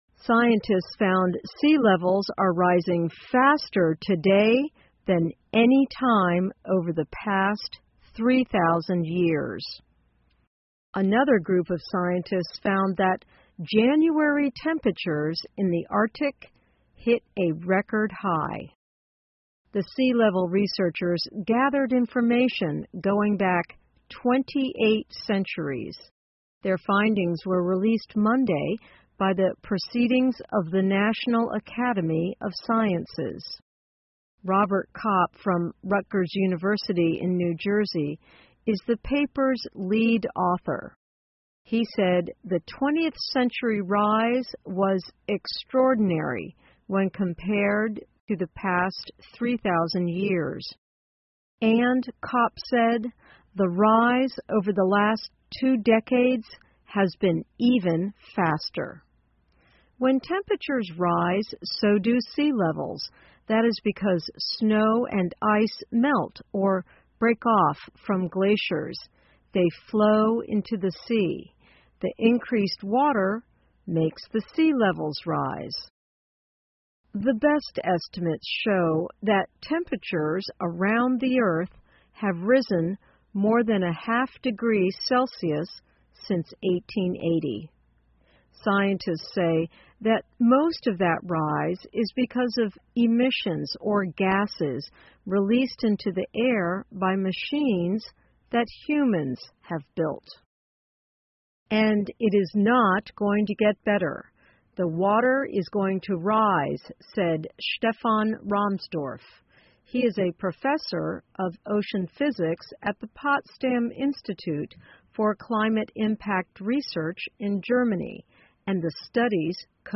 VOA慢速英语2016--北极温度与海平面同上升 听力文件下载—在线英语听力室